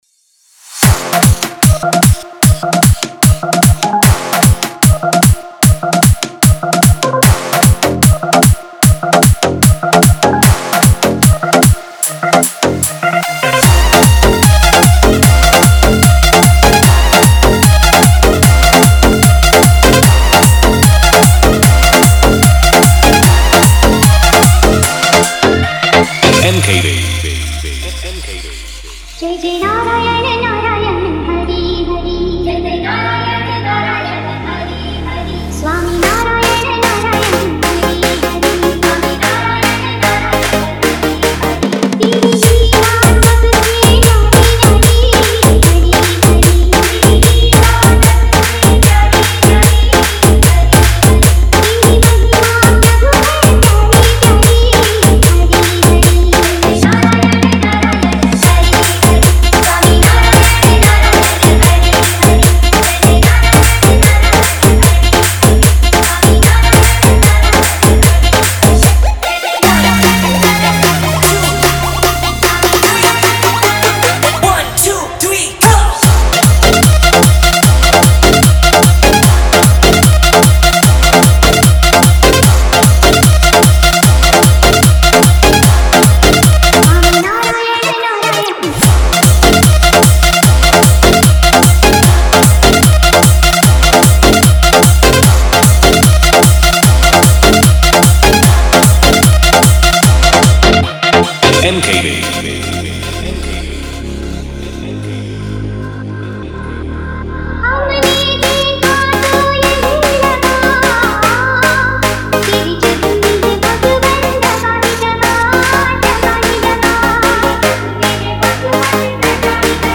Devotional DJ Song, Spiritual DJ Remix
Bhakti DJ Bass Drop